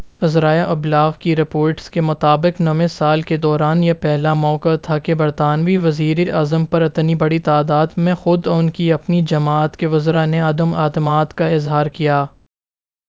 deepfake_detection_dataset_urdu / Spoofed_TTS /Speaker_08 /259.wav